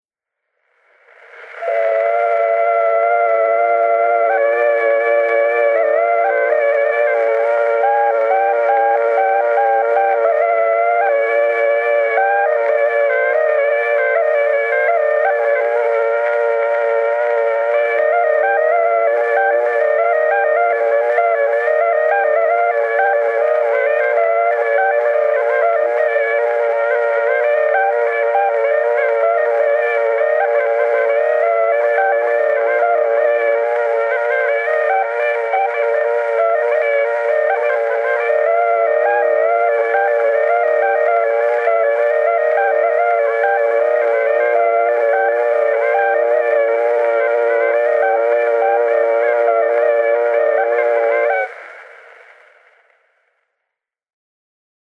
Torupilli Jussi 25. lugu _ Emmaste _ Juhan Maaker _ torupill_folk_noodikogu.mp3